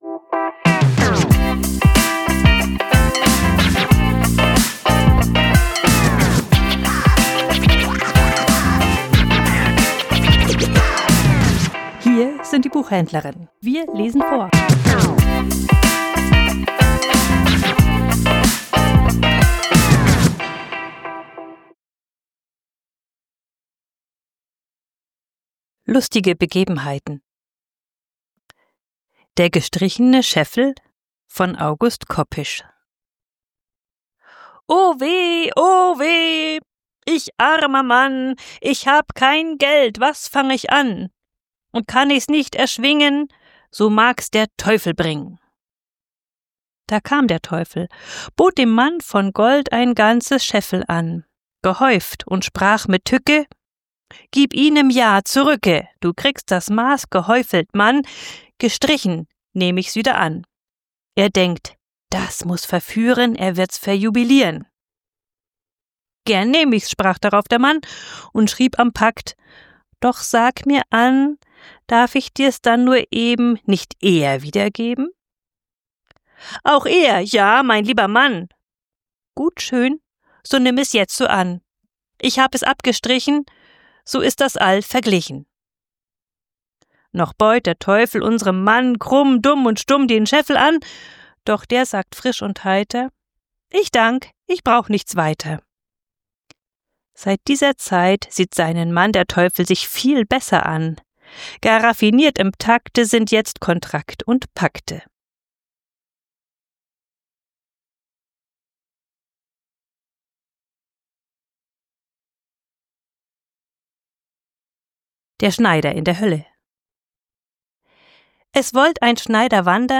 Vorgelesen: Lustige Begebenheiten